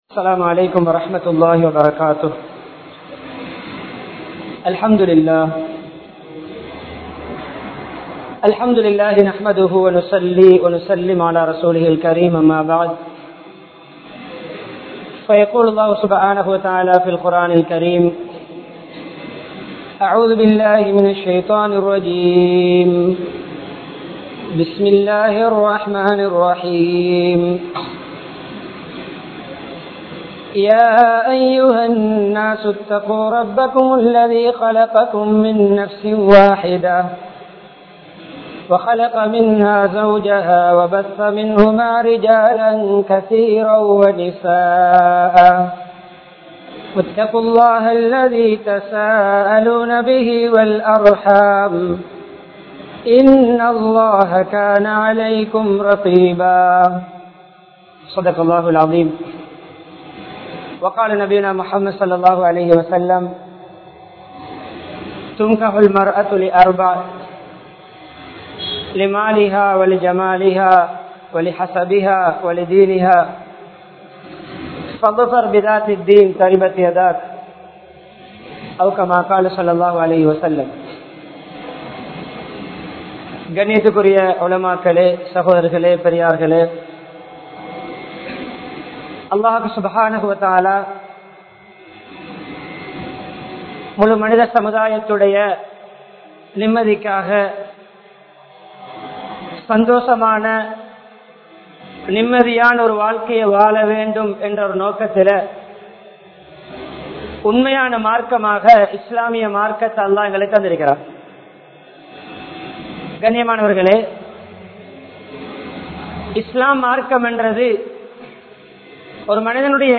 Rose Willa Garden Jumua Masjith